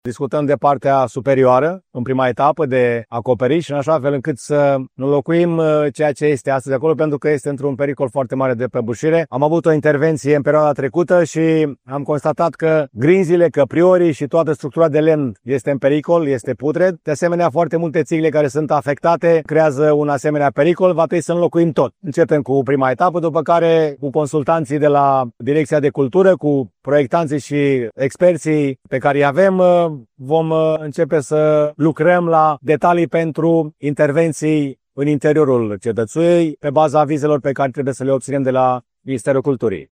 Primarul municipiului Brașov, George Scripcaru: